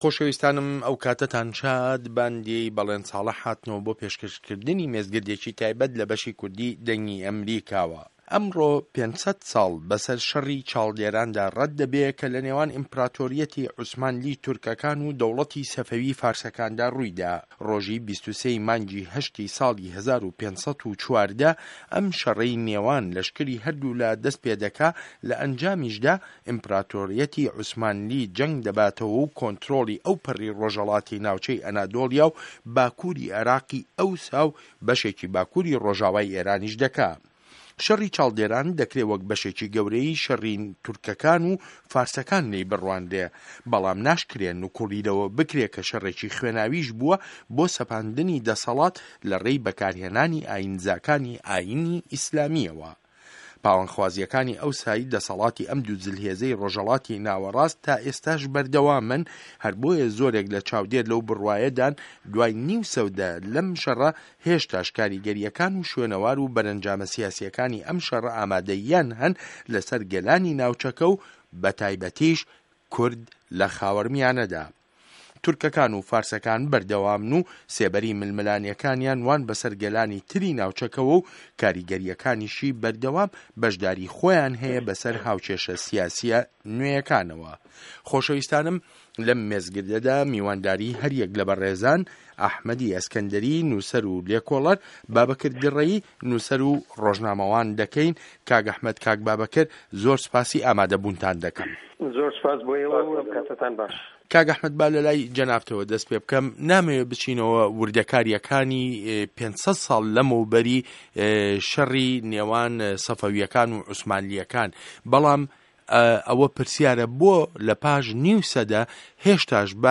مێزگرد : جه‌نگی چاڵدێران پاش 500 ساڵ